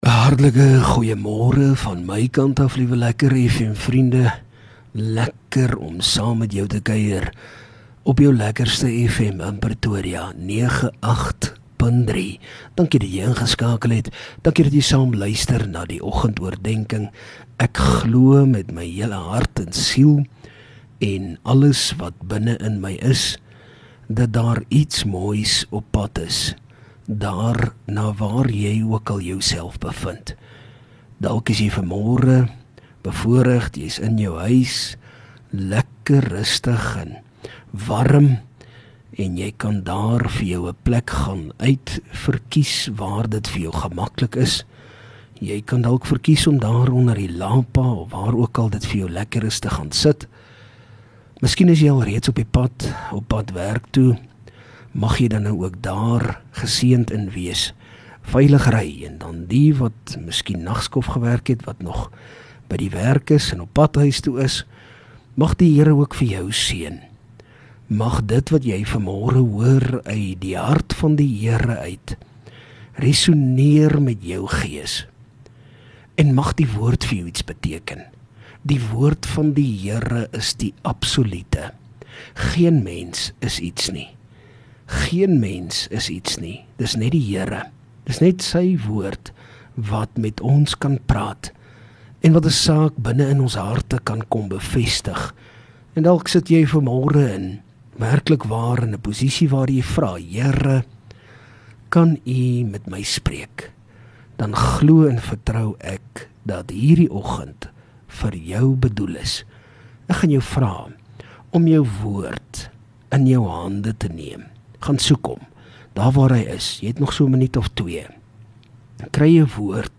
Oggendoordenking